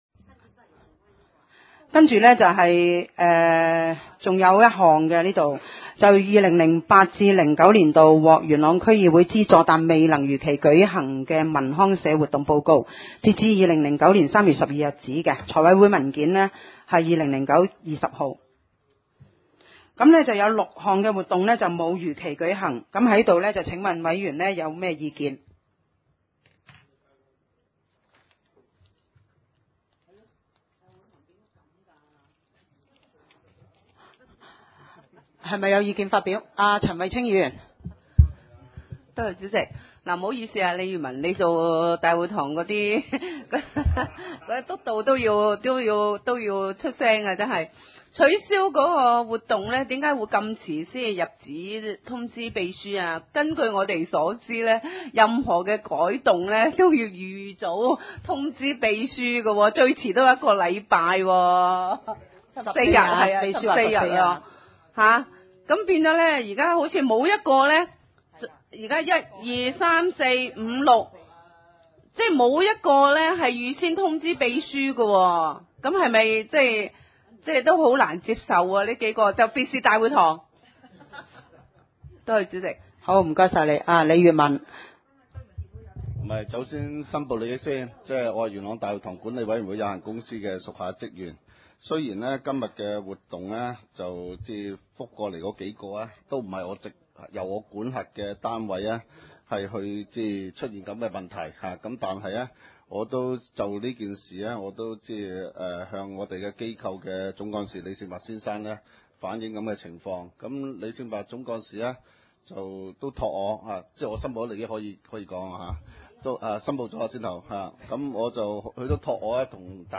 點： 元朗區議會會議廳